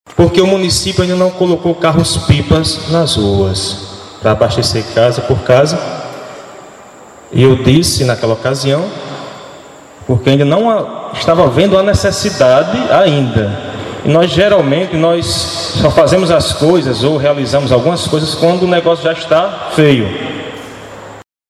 “Nós geralmente só fazemos as coisas ou realizamos algumas coisas, quando o negócio já está feio”, disse o prefeito Valdir Medeiros durante sua fala na audiência pública coordenada pela gestão municipal, realizada na noite da última segunda-feira (16), no ginásio Ailson Lopes. O evento pautou a crise hídrica vivenciada em Jucurutu.